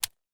aps_firemode_switch.ogg